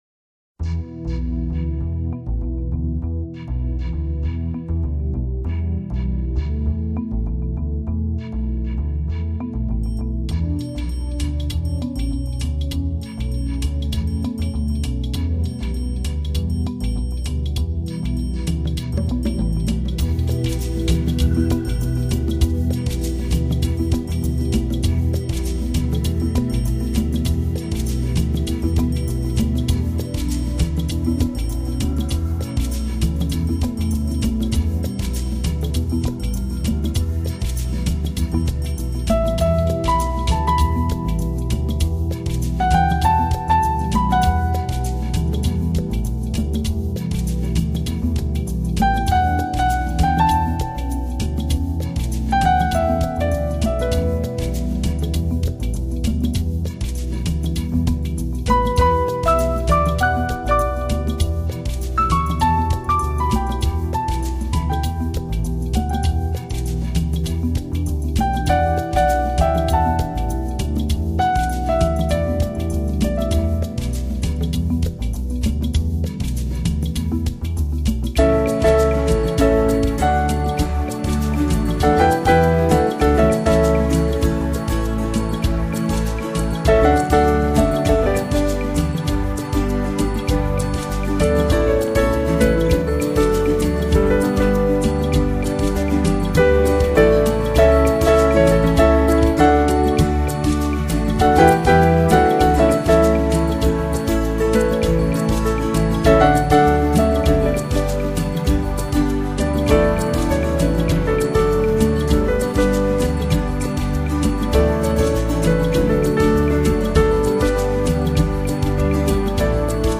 音乐类型：New Age
仰望明灭星辰，彷彿听到，来自天界的空渺音符…
解读星光密码 谱出澄净人心的宁靜乐章：